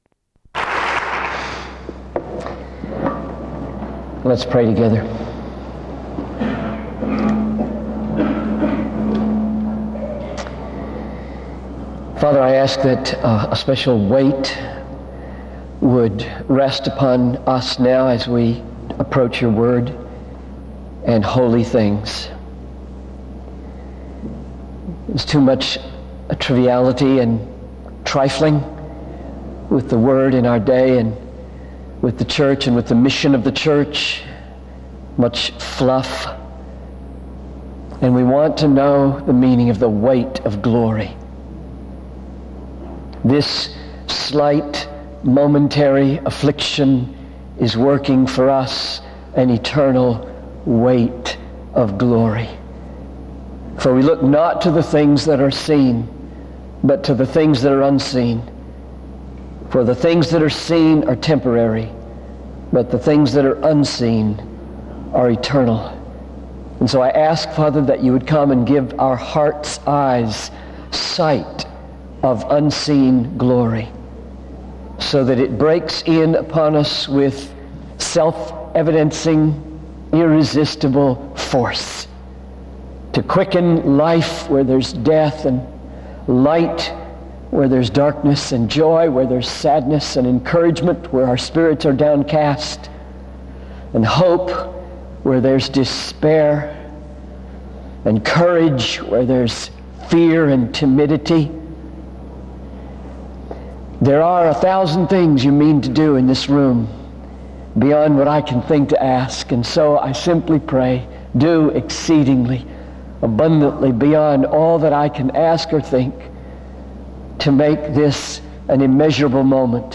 SEBTS_Carver-Barnes_Lecture_John_Piper_2000-09-27_A.wav